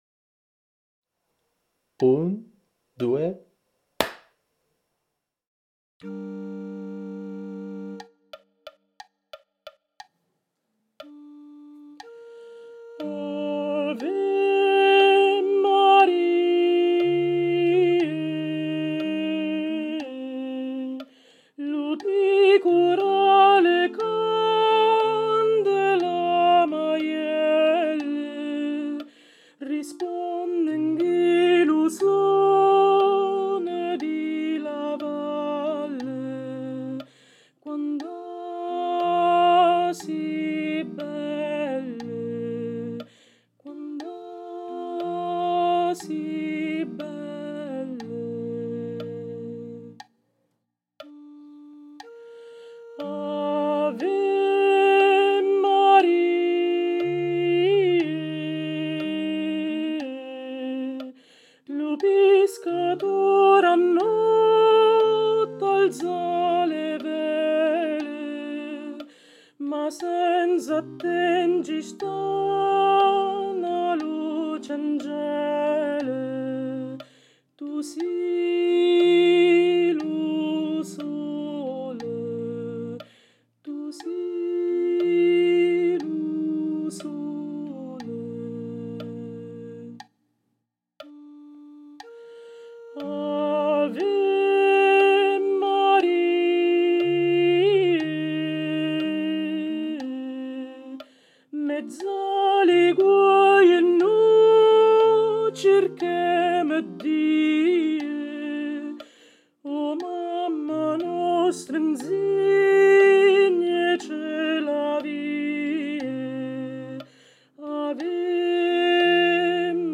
🎧 Voce guida
Alti
• ⚠ Nella traccia guida sentirai una battuta con il tempo a voce, nel "battere" successivo il ciak di inizio (batti una volta le mani come in un "ciak cinematografico"). Avrai un'altra battuta vuota nella quale sentirai l'intonazione della nota di attacco, una ancora di metronomo e quindi inizierai a cantare.